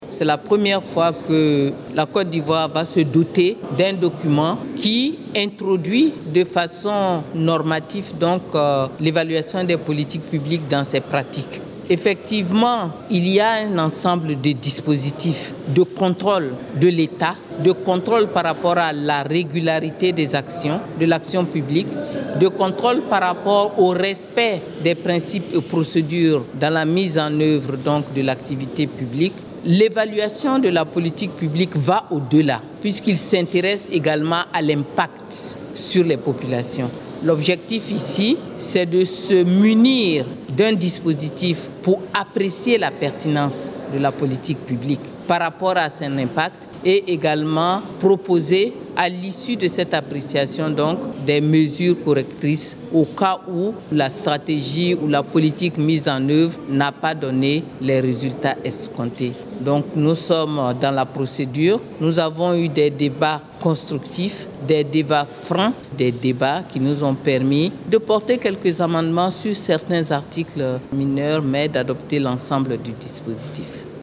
La Ministre KABA NIALE, au sortir de cette séance…………………